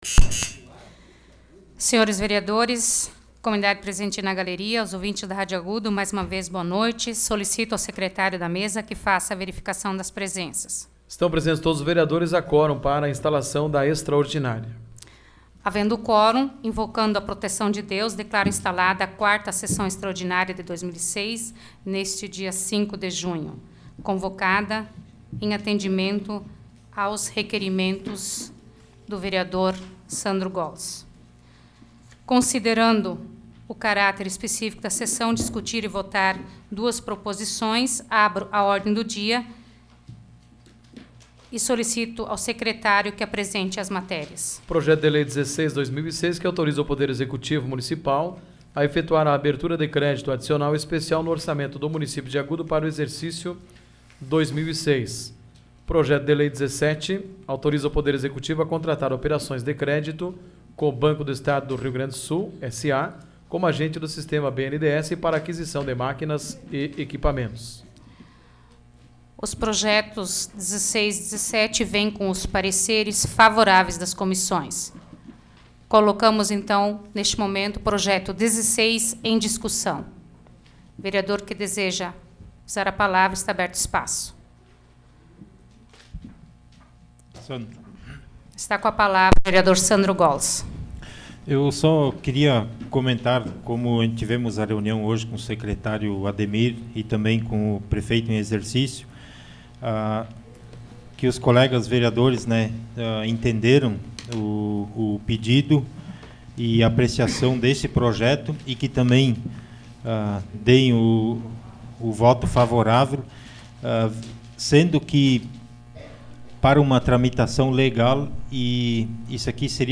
Áudio da 19ª Sessão Plenária Extraordinária da 12ª Legislatura, de 05 de junho de 2006